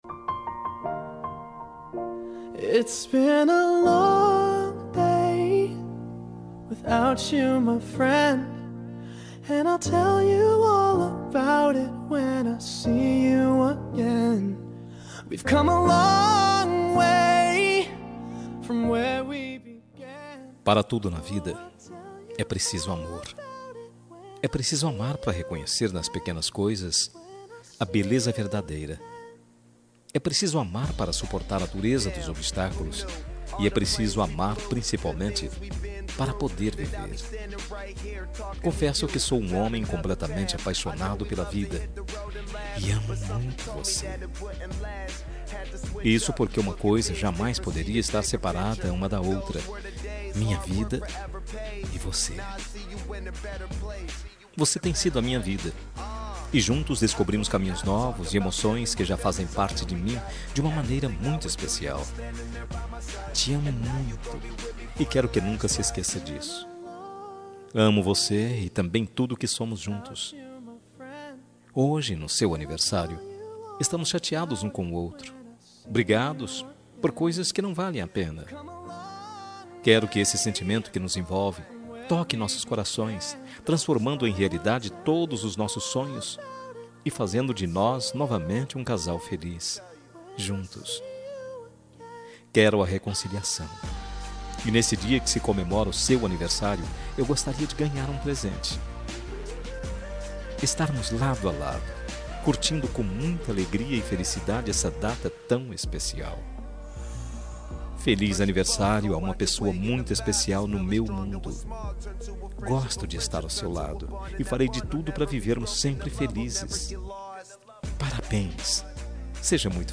Aniversário Romântico com Reconciliação – Voz Masculina – Cód: 5459